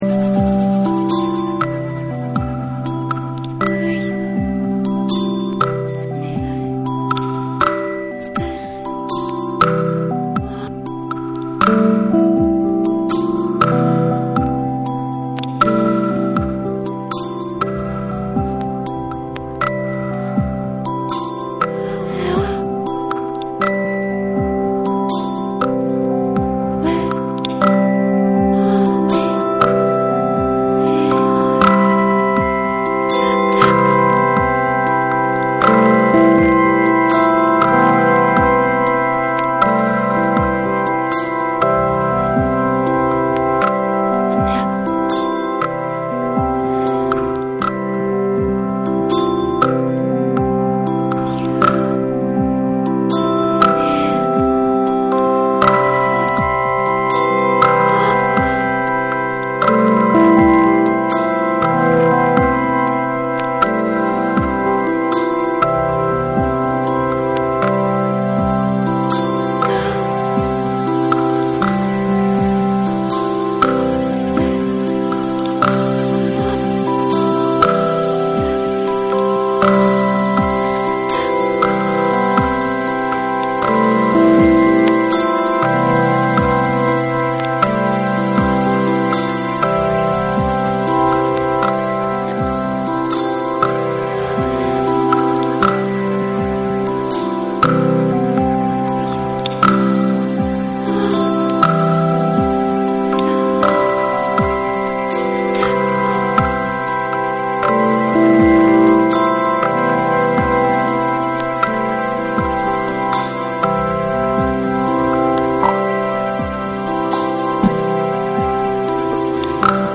BREAKBEATS / CHILL OUT